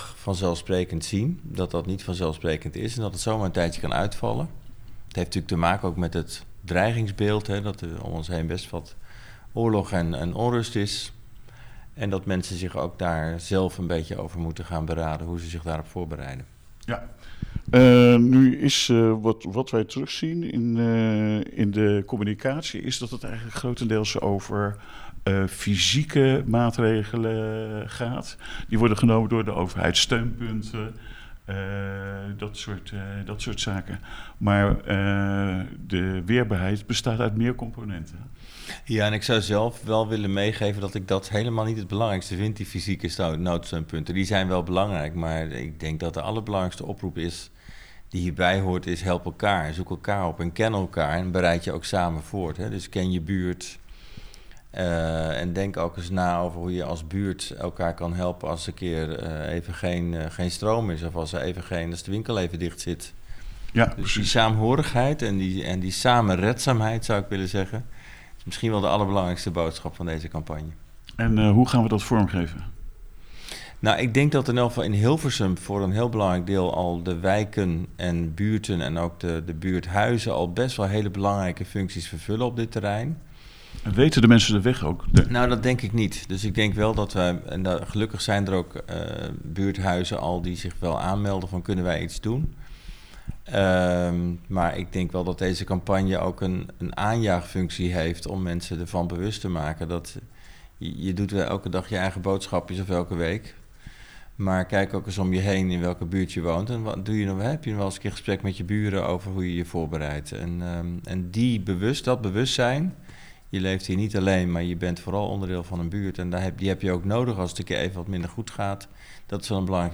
Aan de voorzitter van de Veiligheidsregio maar eens even vragen wat de campagne inhoudt en wat het moet opleveren. We vallen midden in het gesprek over de uitval van vitale infrastructuur.